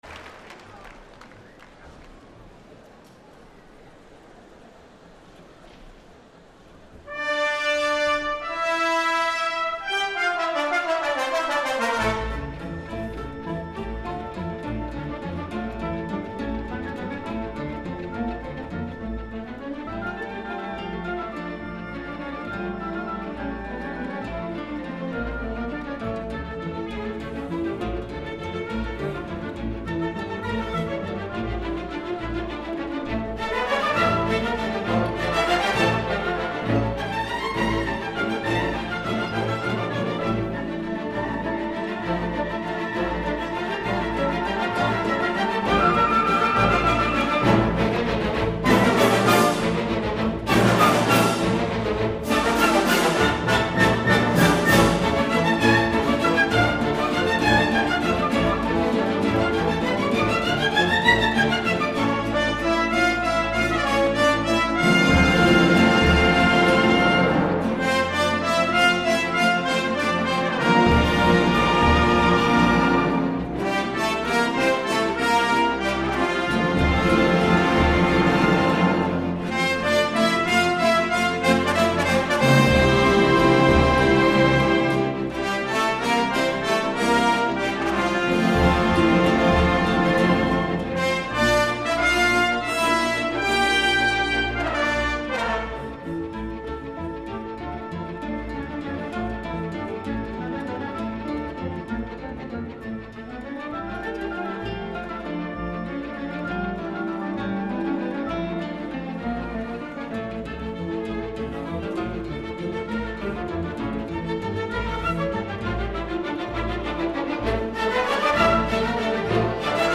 本片全长102分钟，影音画质皆属上乘发烧极品，现场收音及临场感极佳，无论是音效、画质以及演出皆属一流水准。
聆赏这张DVD彷佛就是置身现场，让您深深的体会剧院组的古典现场音效。